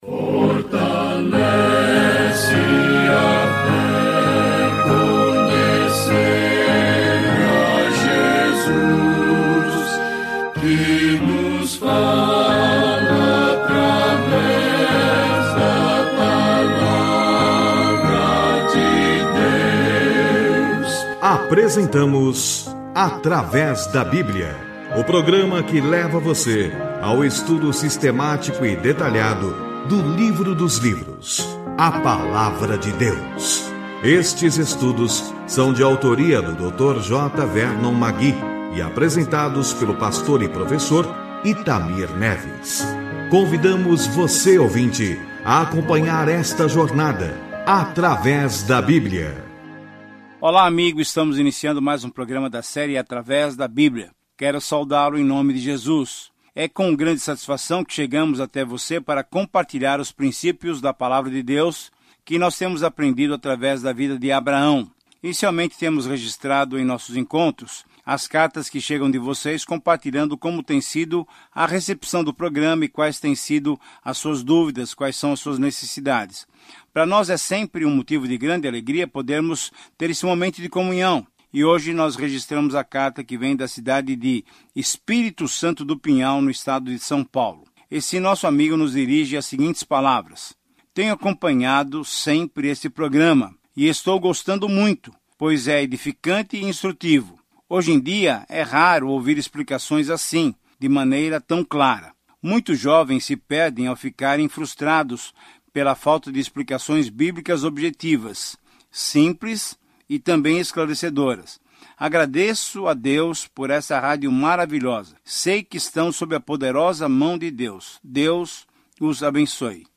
Viaje diariamente por Gênesis enquanto ouve o estudo em áudio e lê versículos selecionados da palavra de Deus no livro de Gênesis.